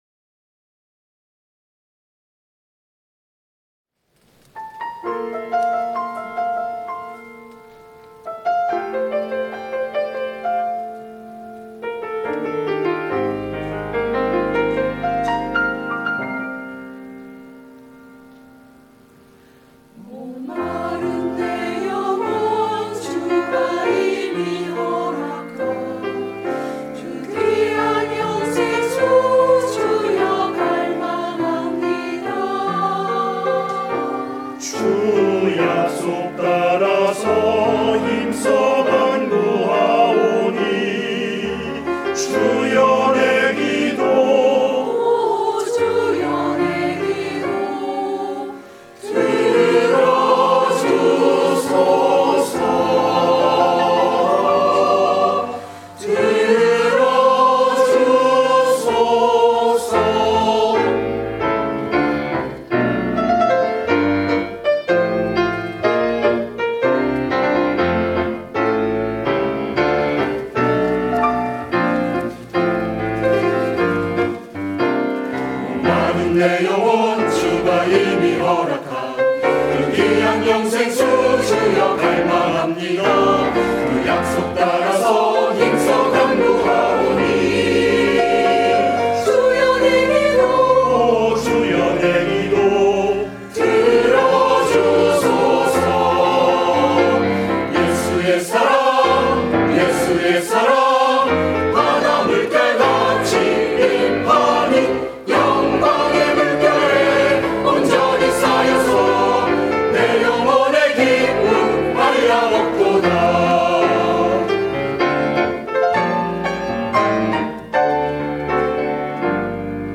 시온